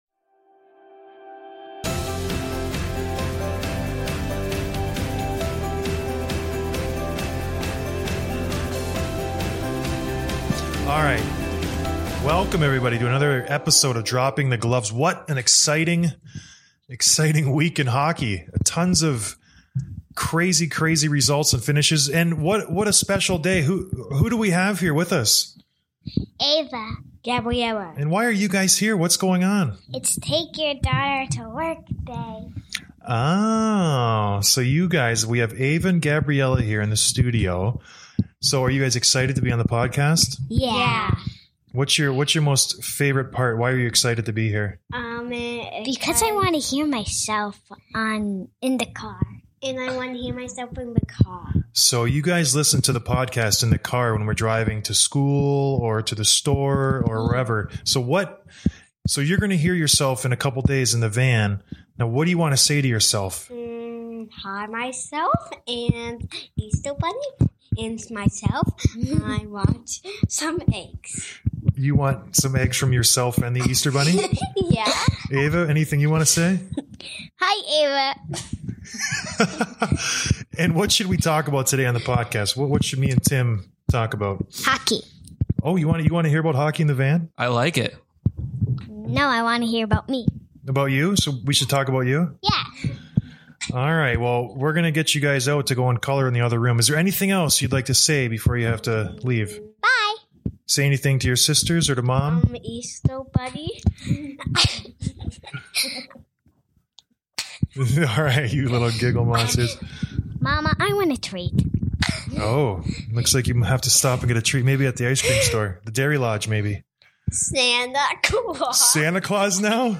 Game 7 hero Barclay Goodrow was kind enough to call into the show this week. We chat about what the energy was like on the bench after being down 3-0, how Pavs is doing, what if felt like to score the game-winner, and how the boys are preparing for Colorado.